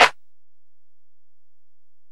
Snare (59).wav